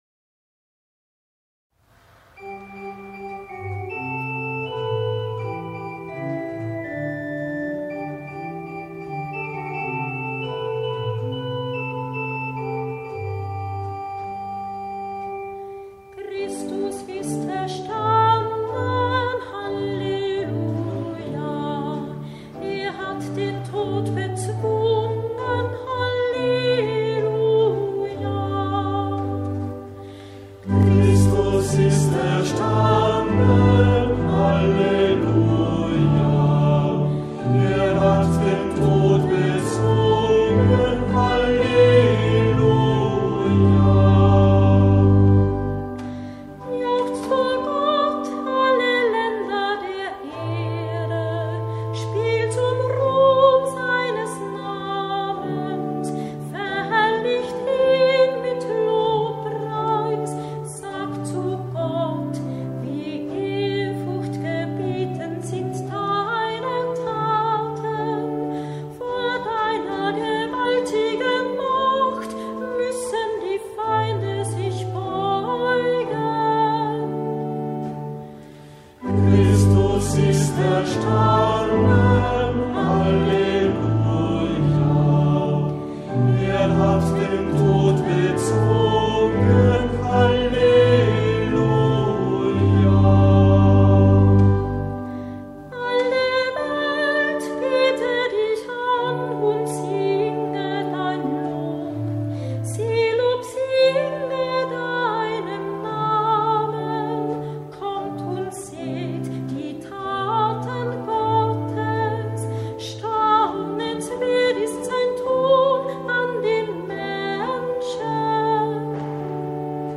Psalmen aus dem Gurker Psalter für Kantor mit Orgel- oder Gitarrenbegleitung finden Sie hier, geordnet nach den Lesejahren ABC und den Festen bzw.